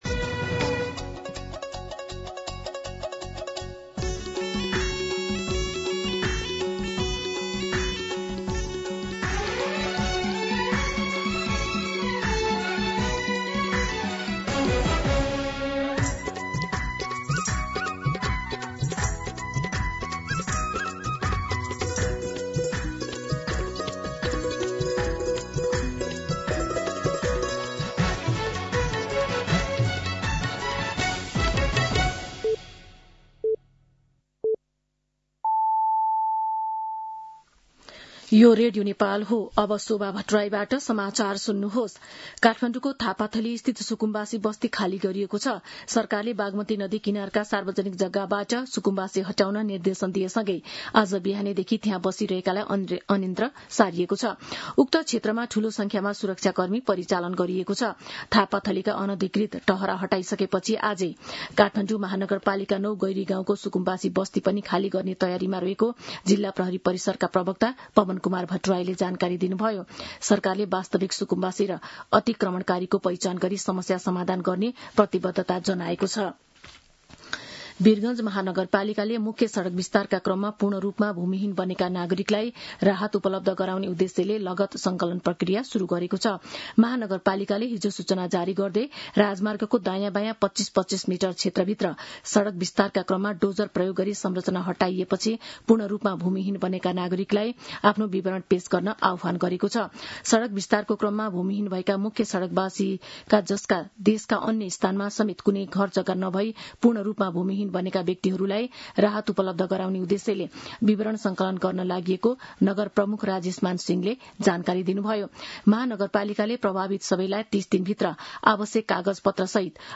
मध्यान्ह १२ बजेको नेपाली समाचार : १२ वैशाख , २०८३